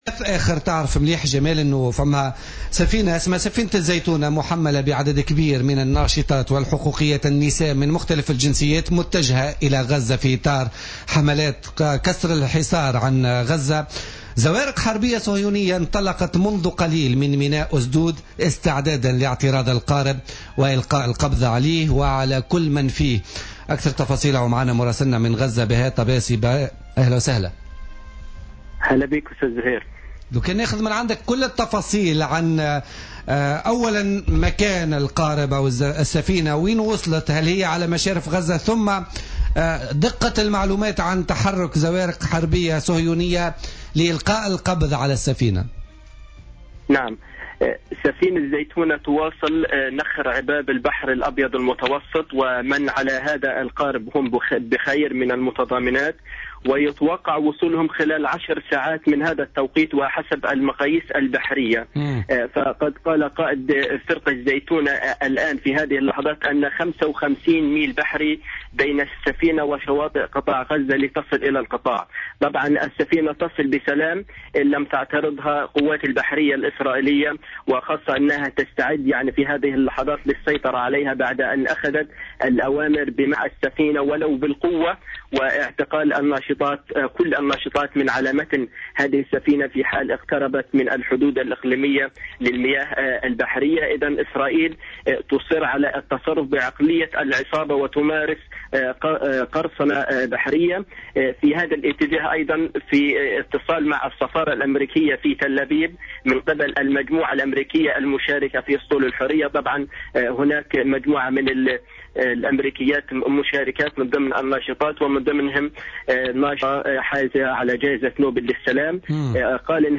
في مداخلة له اليوم في برنامج "بوليتيكا"